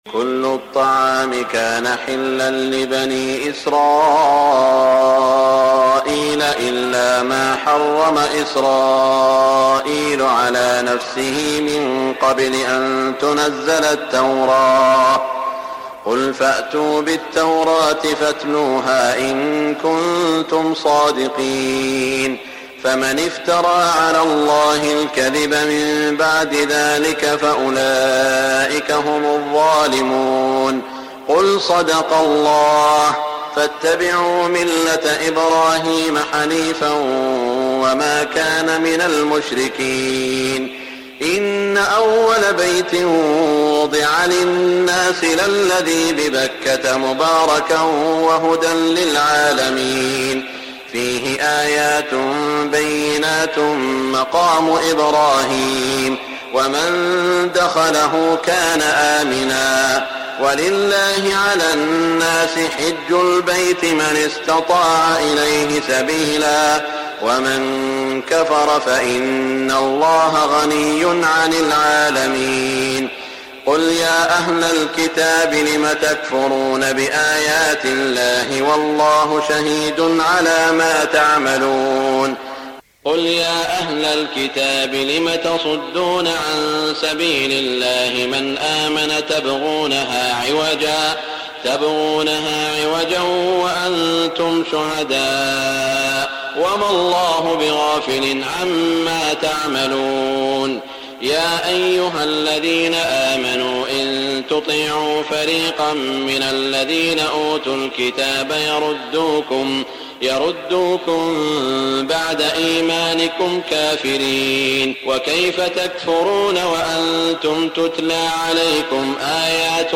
تهجد ليلة 24 رمضان 1418هـ من سورة آل عمران (93-185) Tahajjud 24 st night Ramadan 1418H from Surah Aal-i-Imraan > تراويح الحرم المكي عام 1418 🕋 > التراويح - تلاوات الحرمين